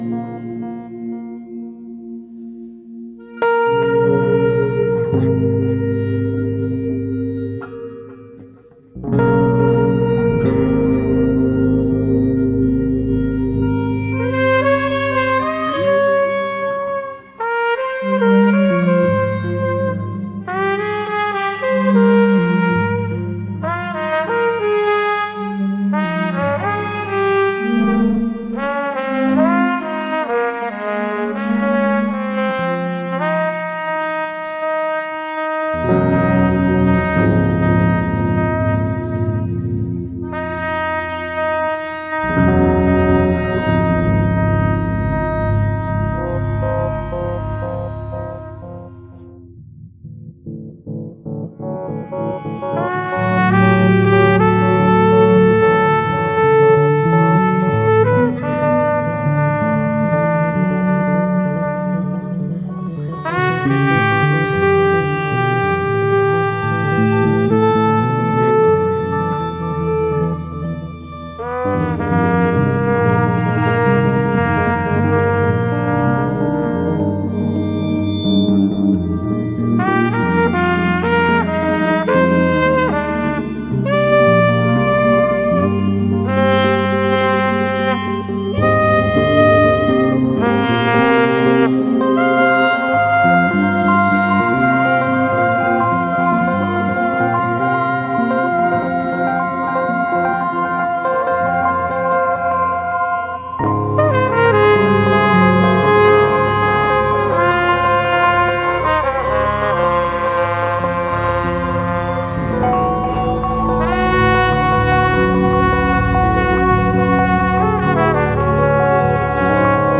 in its straddling of jazz, rock, and electronic production.